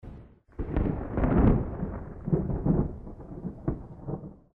thunder4.mp3